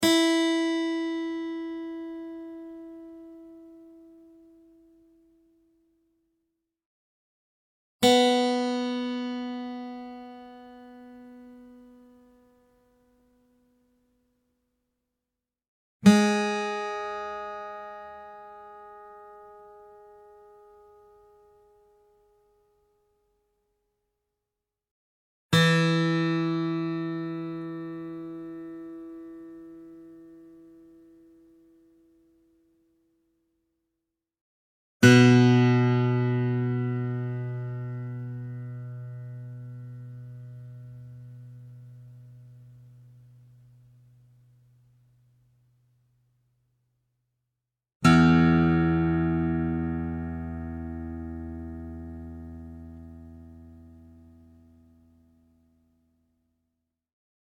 Real acoustic guitar sounds in Open E Tuning
Guitar Tuning Sounds